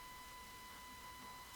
Störgeräusch bei Mikrofonaufnahme
Ich habe einen AMD A8-6600K 3.9 GHz mit Windows 10 / 64bit und ein BM USB Großmembran Mikrofon für ca. 100,00 Euro und trotz Micsreen ein Störgeräusch, das immer gleichbleibend ist und sich wie ein digitales Grundrauschen anhört.
Anhänge Digitales_Stoerrauschen_laut.mp3 38 KB · Aufrufe: 147